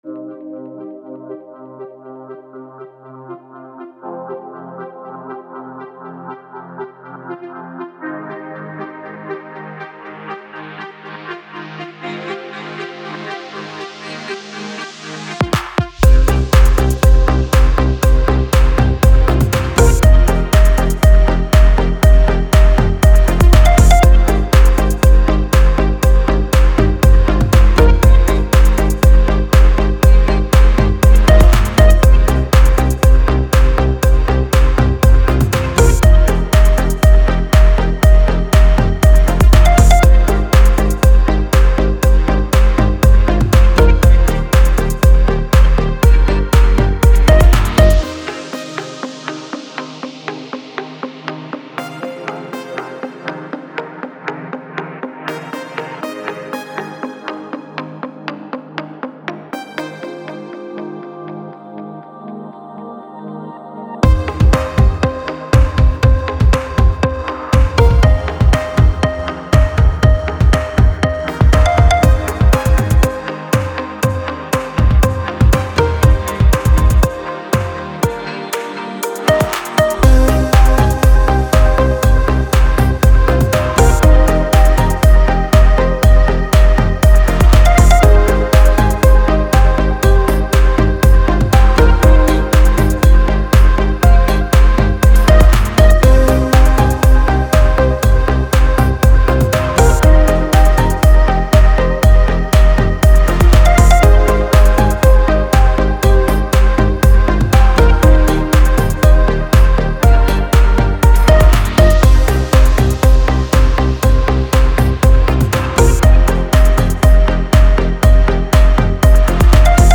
the best instrumental music for your creative projects.